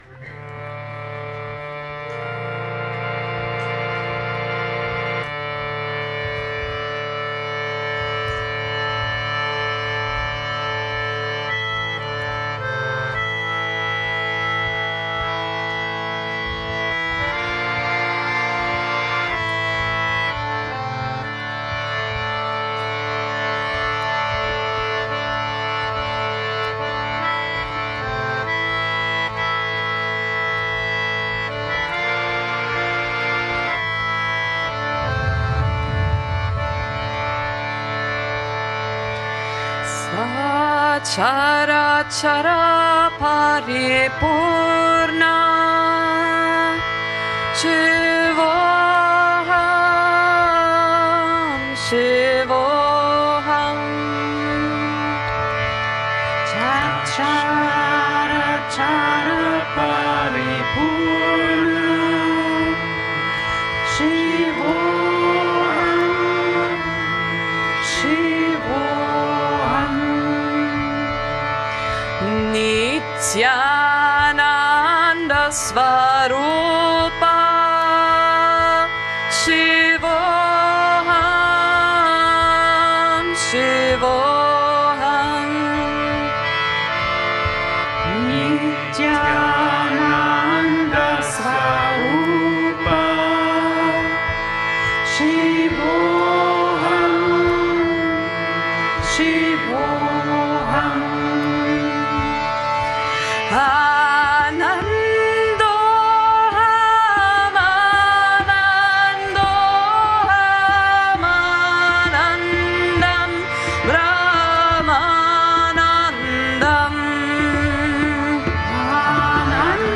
Mantra, Kirtan and Stotra: Sanskrit Chants
during a saturday evening satsang
Sachara Chara Pari Purna Shivoham is a Vedanta kirtan that expresses the unity of the individual soul with the cosmic soul: Shivoham, I am Shiva, is the refrain.